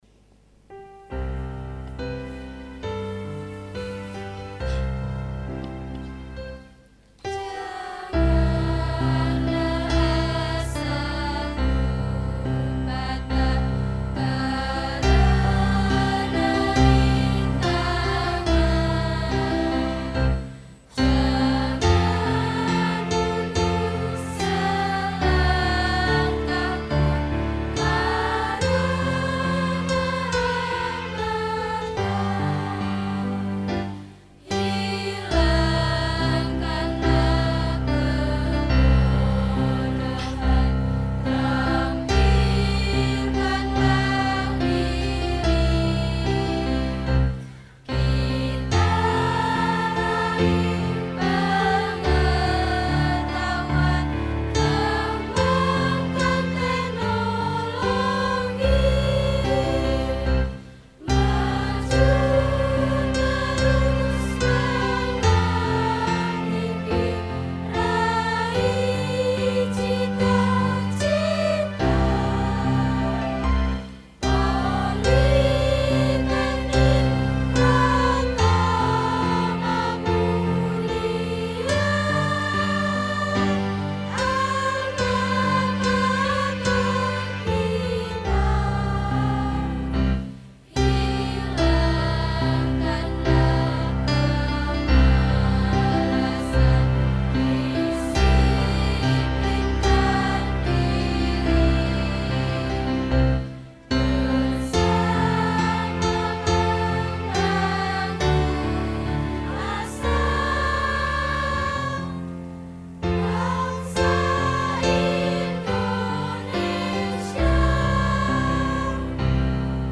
Paduan Suara Mahasiwa Politama